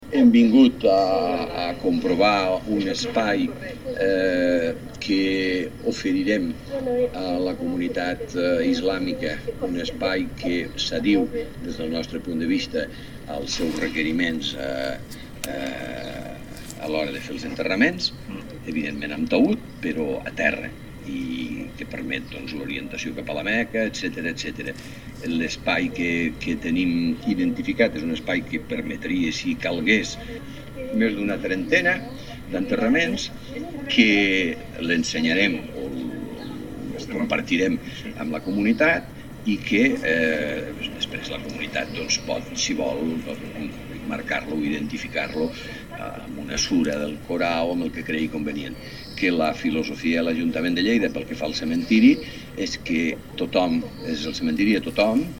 tall-de-veu-de-lalcalde-miquel-pueyo-sobre-lespai-que-la-paeria-oferira-a-la-comunitat-islamica-al-cementiri-de-lleida